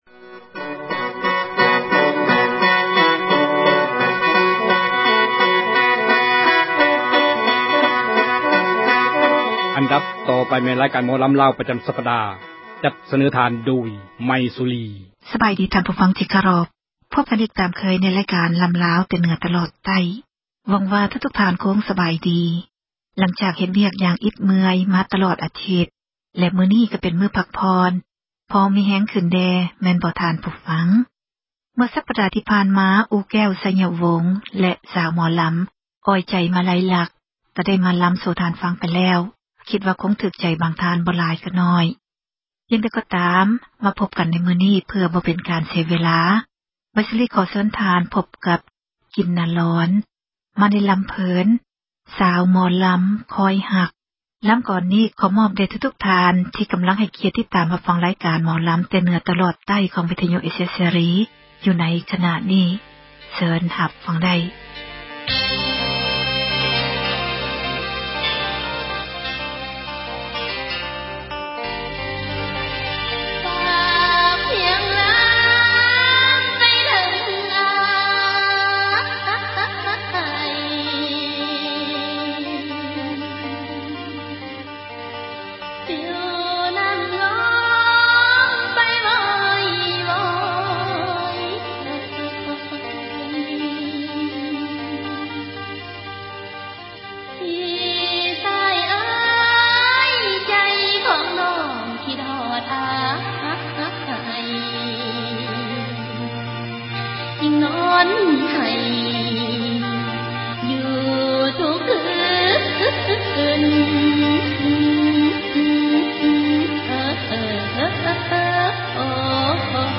ຣາຍການ ໝໍລໍາລາວ ປະຈໍາສັປດາ ຈັດສເນີທ່ານ ໂດຍ